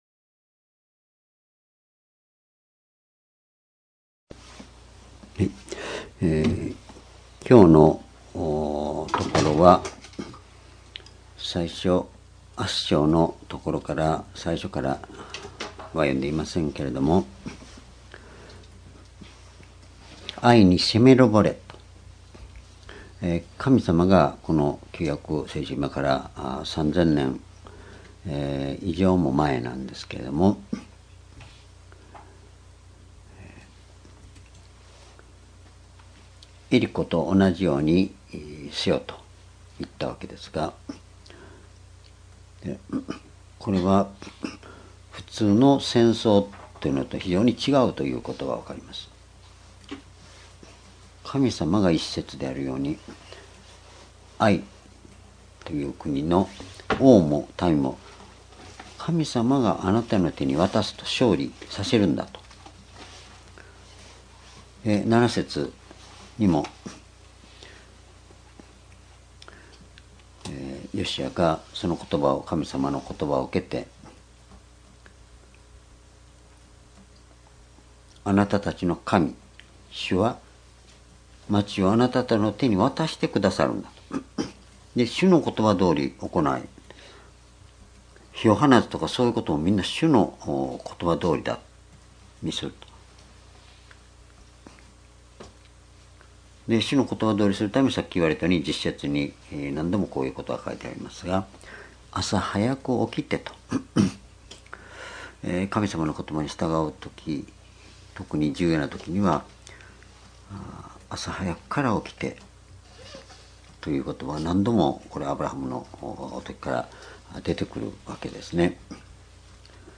｢｣ヨシュア記8章--2019年2月5日 夕拝
（主日・夕拝）礼拝日時 2019年2月5日 夕拝 聖書講話箇所 ヨシュア記8章 ※視聴できない場合は をクリックしてください。